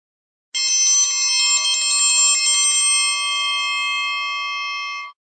Звуки треугольника
Звонок на обед